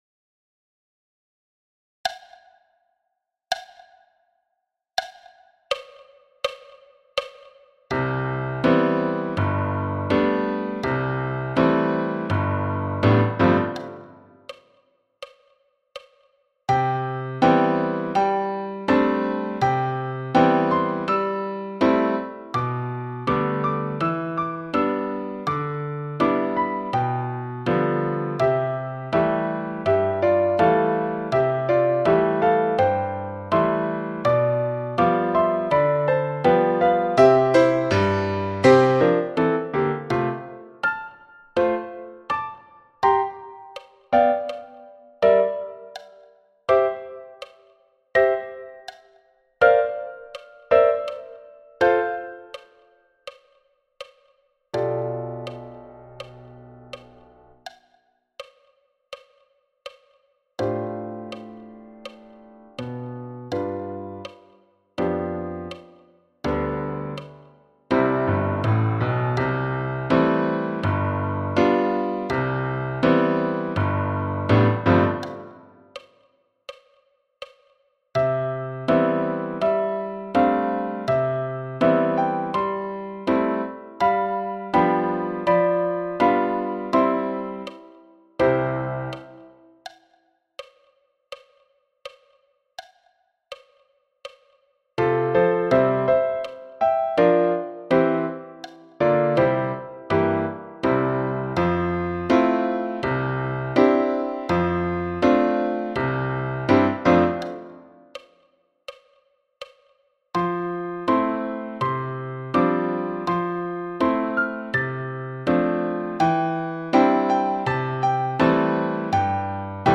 Aurora à 82 bpm